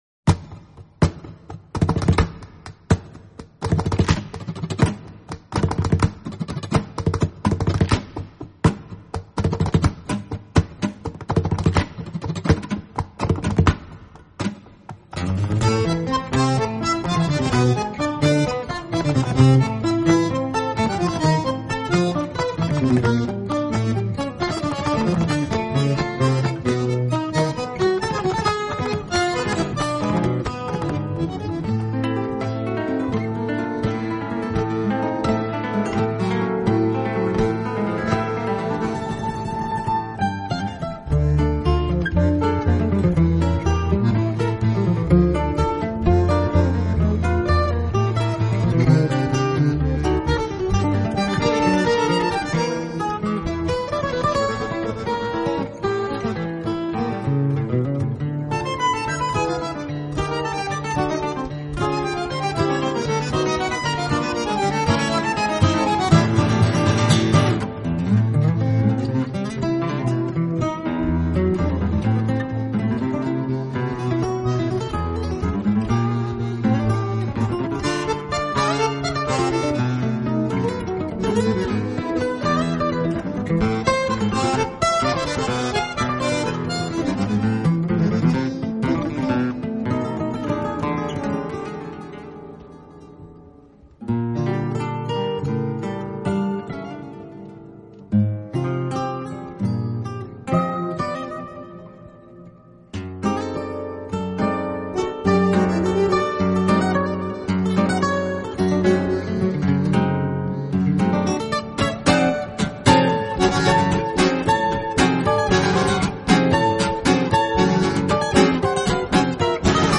意裔美籍吉他大师不朽巨作
热情的节奏与奔放的旋律缠绵
摇滚爵士拉丁探戈燃烧热情烈火